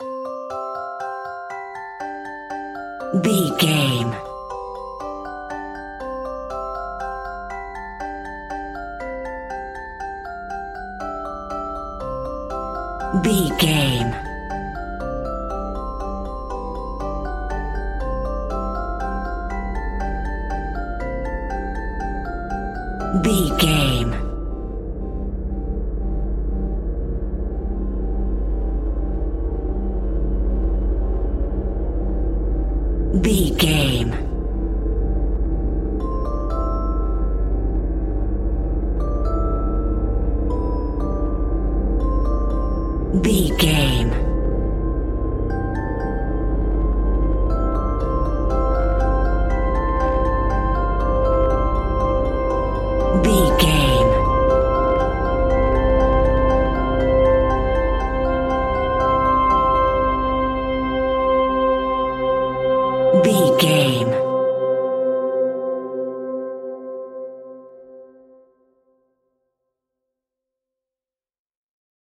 Horror Film Music Sounds.
In-crescendo
Ionian/Major
ominous
dark
suspense
haunting
eerie
synthesiser
electric piano
ambience
pads